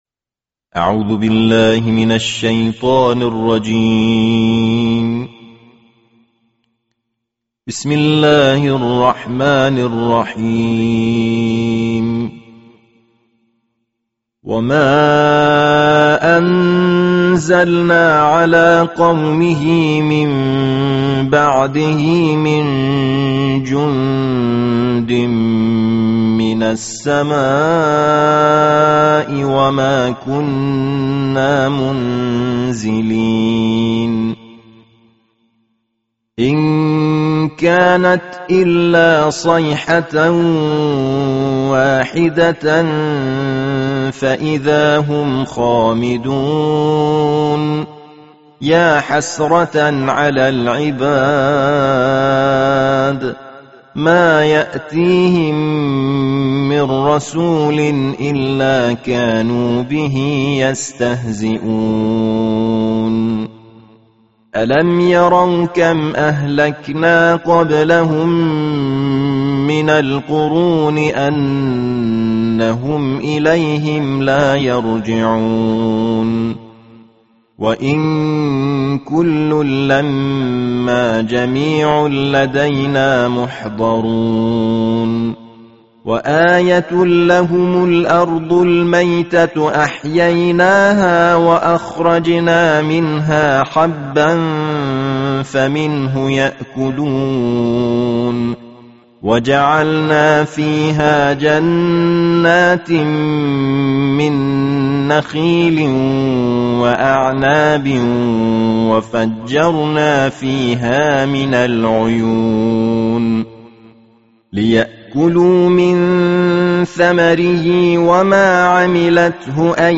সুললিত কণ্ঠে ২৩তম পারার তিলাওয়াত